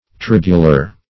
tribular - definition of tribular - synonyms, pronunciation, spelling from Free Dictionary
Search Result for " tribular" : The Collaborative International Dictionary of English v.0.48: Tribual \Trib"u*al\, Tribular \Trib"u*lar\, a. Of or relating to a tribe; tribal; as, a tribual characteristic; tribular worship.